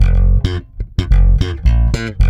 -AL DISCO.G.wav